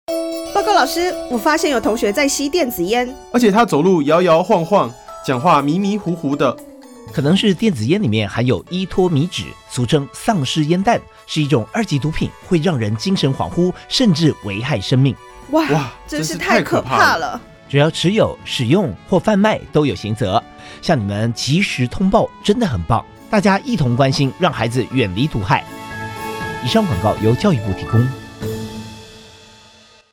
轉知 教育部製作防制依托咪酯廣播廣告